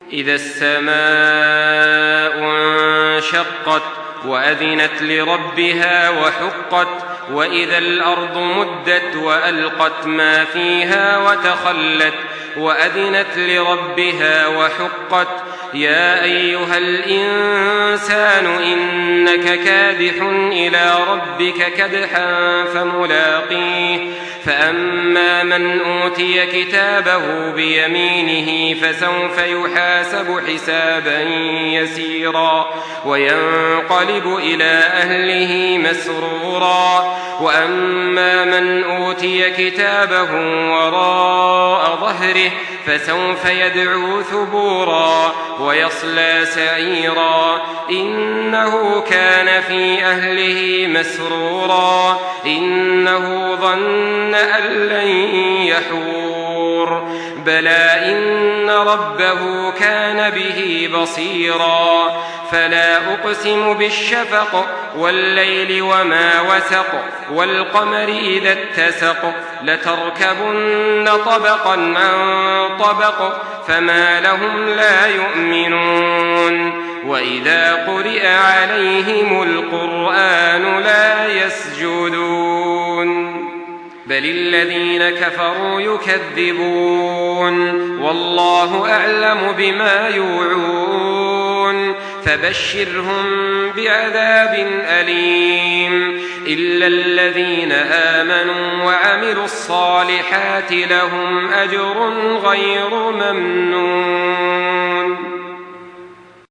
Surah Al-Inshiqaq MP3 by Makkah Taraweeh 1424 in Hafs An Asim narration.
Murattal